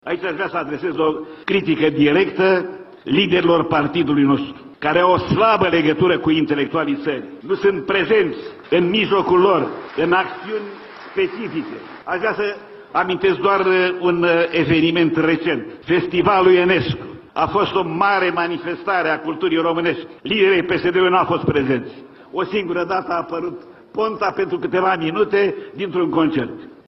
Preşedintele de onoare al PSD, Ion Iliescu, a criticat, la congresul PSD, alegerile interne de săptămâna trecută, prin care Liviu Dragnea a fost ales preşedinte al formatiuni.